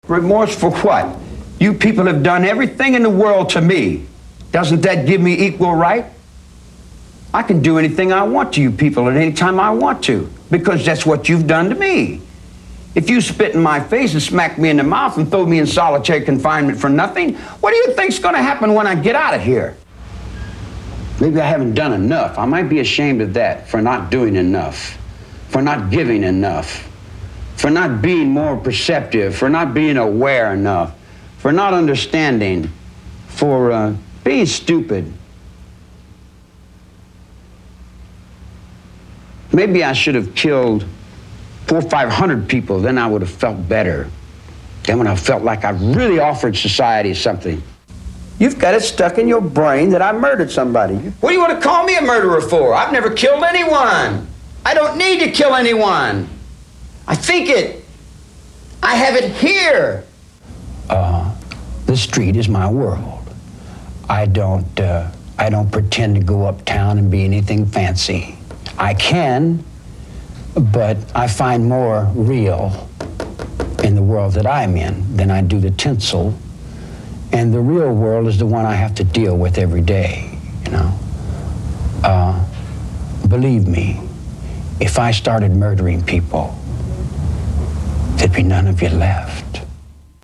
MANSON'S 1997 PAROLE HEARING
The proceedings, which lasted about an hour, were broadcast by Court TV.
Charles Manson - Giving a Speech to Parole Officers.mp3